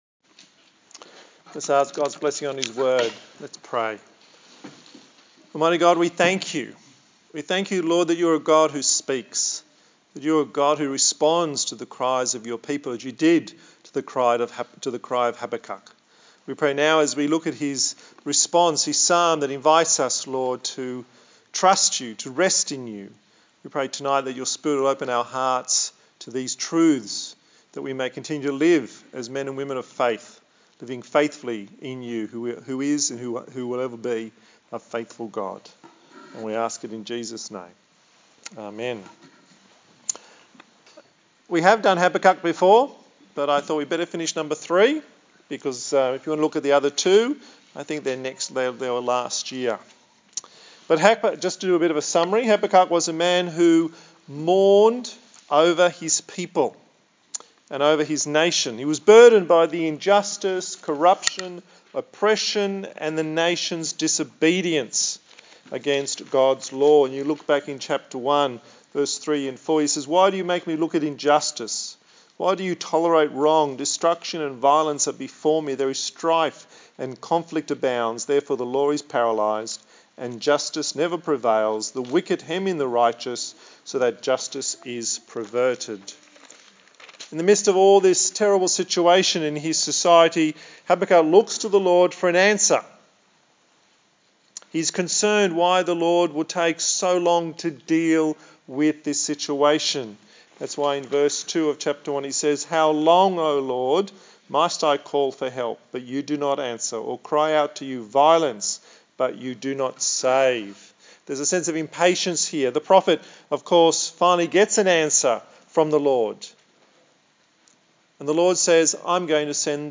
Service Type: TPC@5